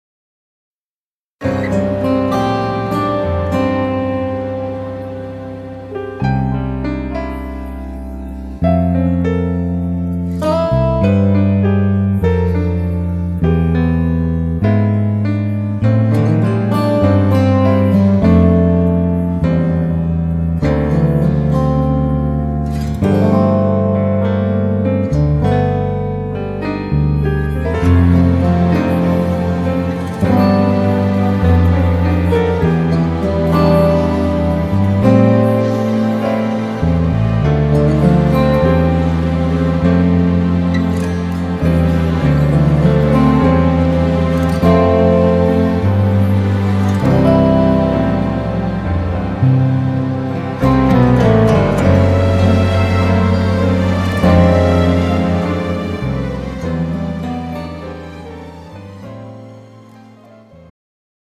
음정 -1키
장르 축가 구분 Pro MR